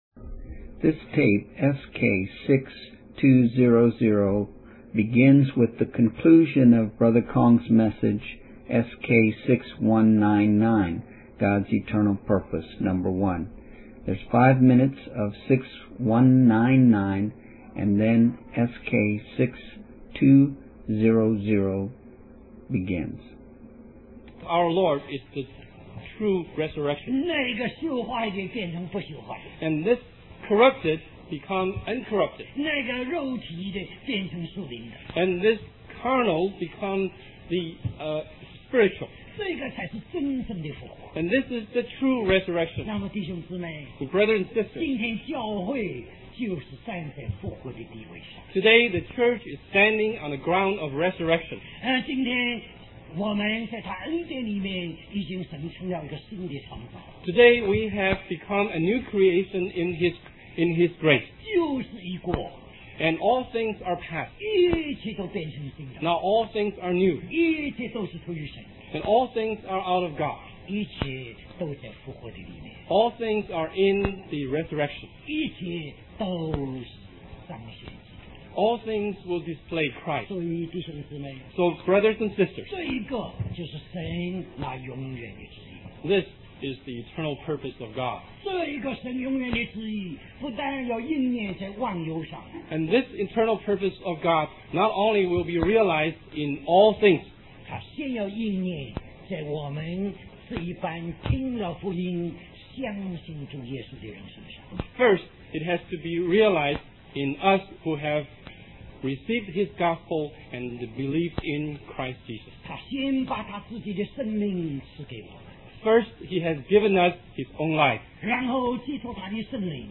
West Coast Christian Conference